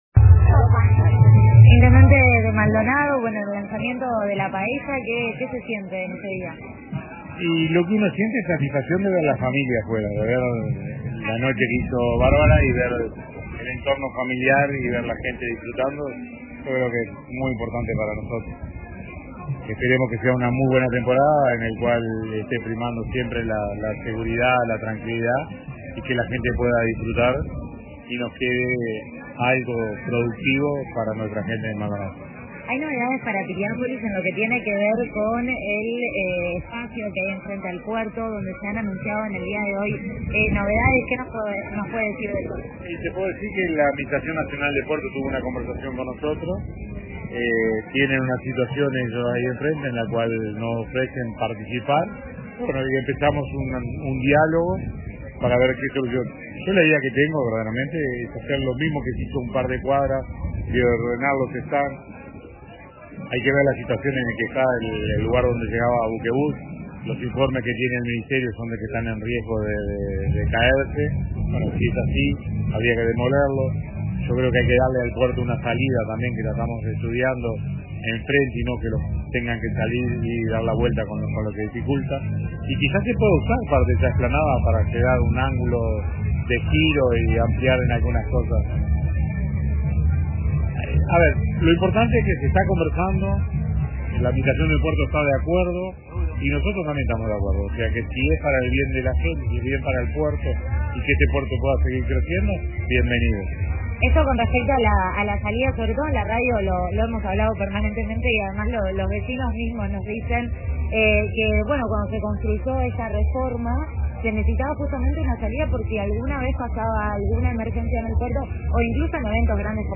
El intendente de Maldonado, Miguel Abella, participó de la 26.ª edición de la tradicional Paella Gigante y dialogó con RADIO RBC